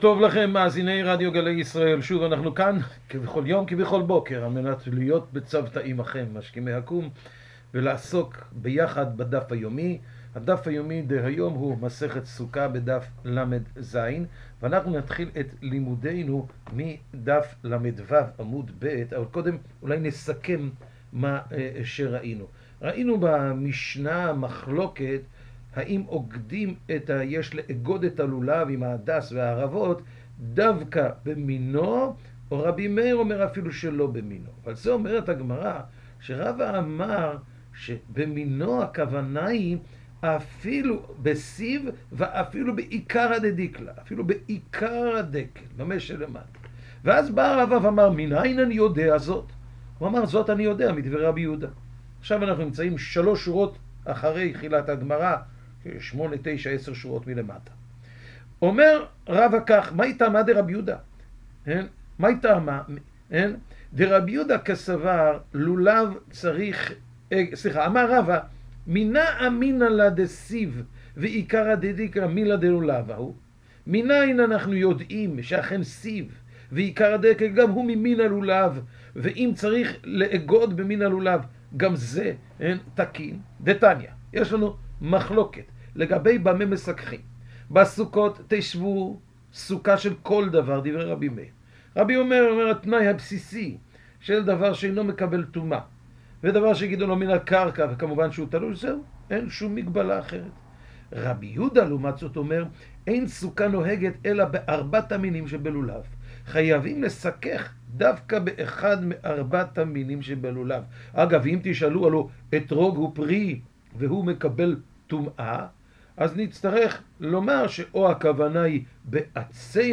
השיעור משודר בשעה 05:30 בבוקר ברדיו גלי ישראל וכל היום באתר סרוגים.